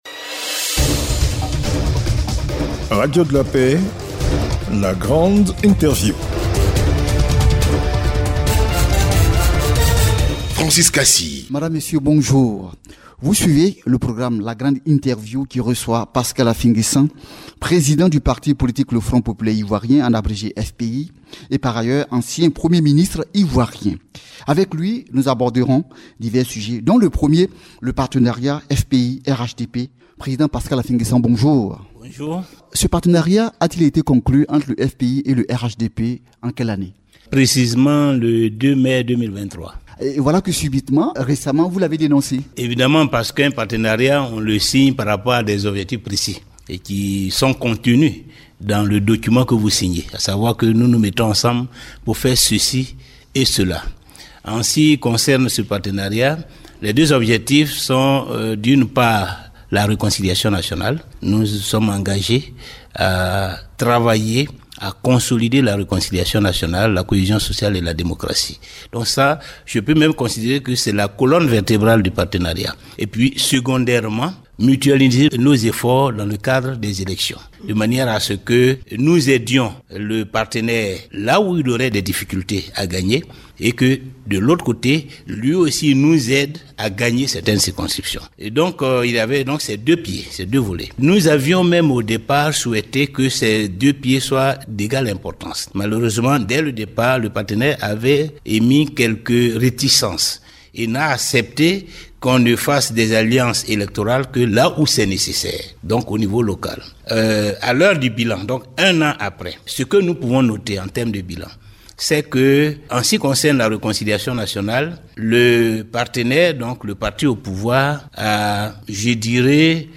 la-grande-interview-professeur-pascal-affi-nguessan-lappel-de-gbagbo.mp3